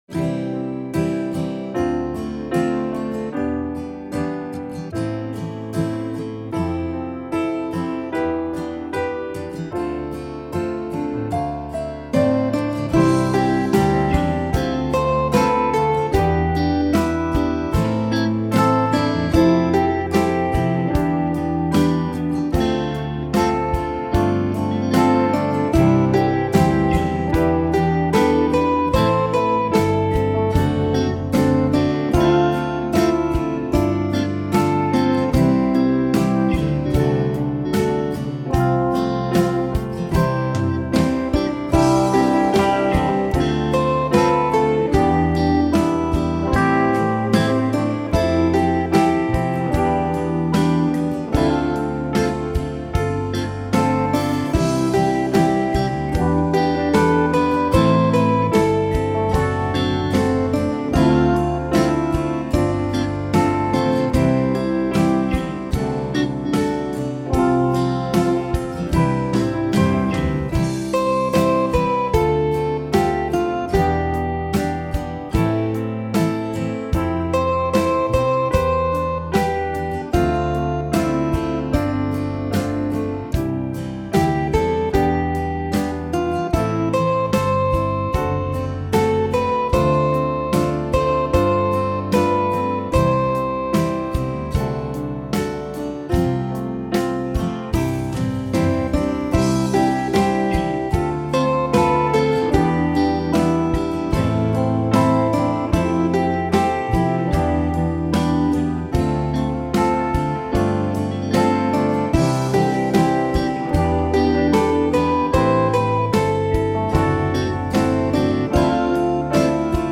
My backing is at 75bpm.